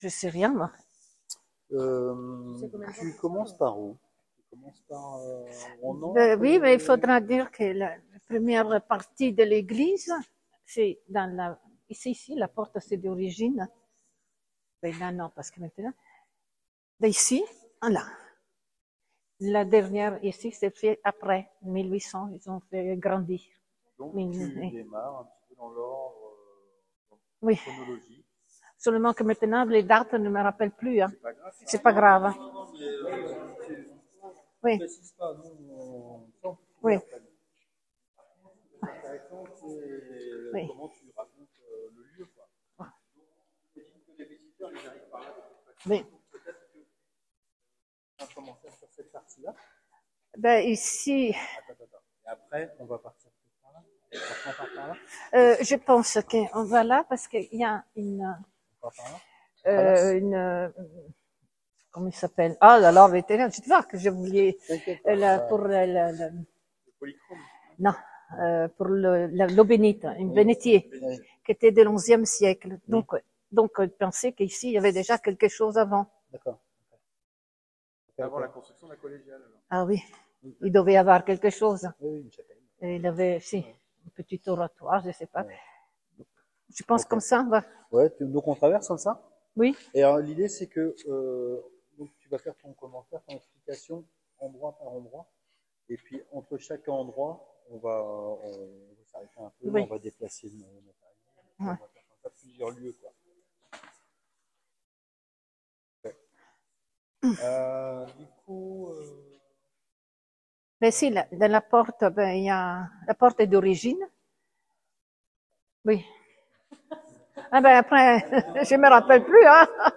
Journée du patrimoine Novembre 2025